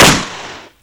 pow_1.wav